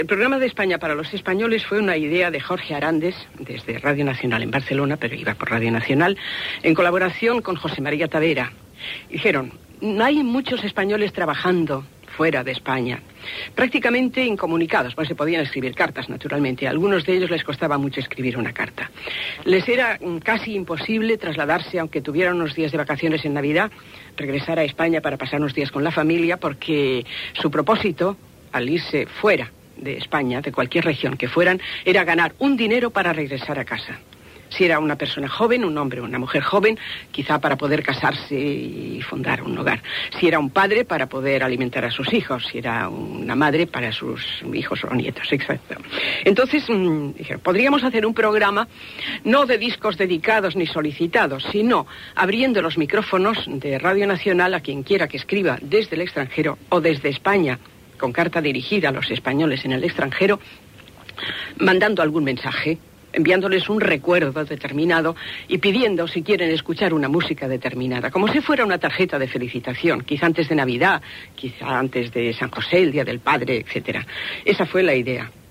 Divulgació
Programa presentat per Joan Manuel Serrat.